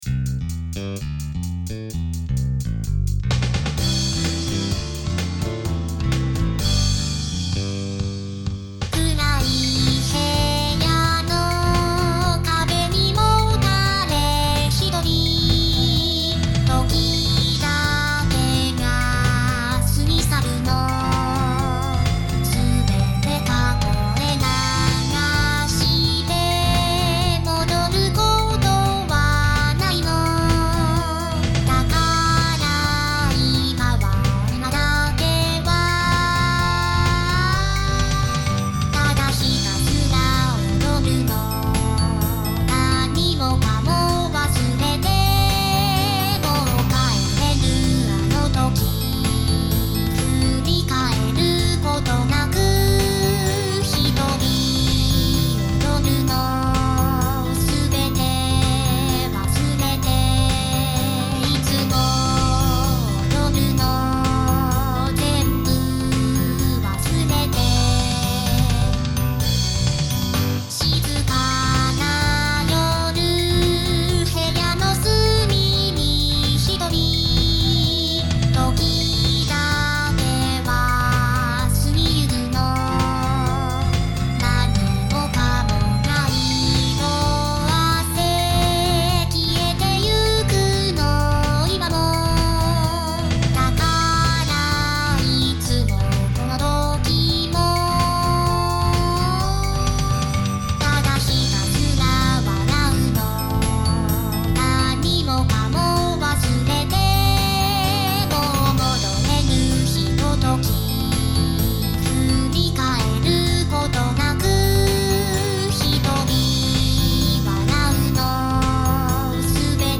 VSTi